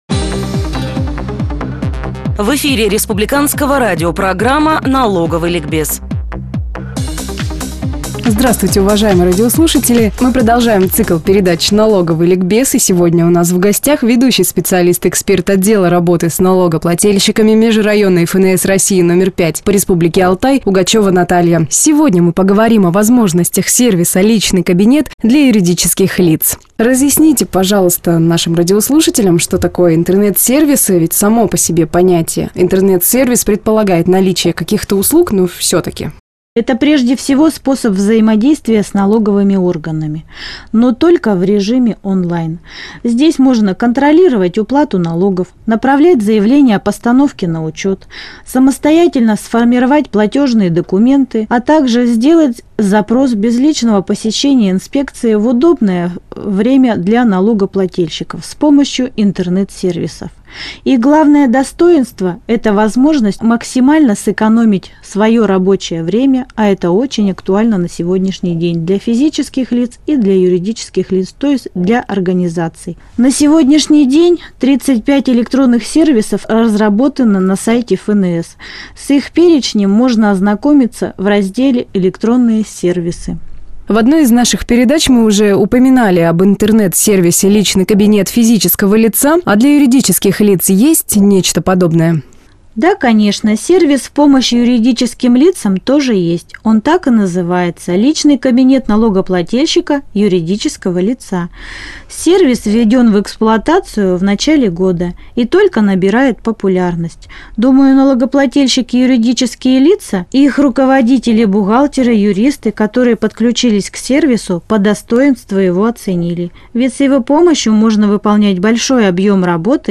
В эфире «Радио России»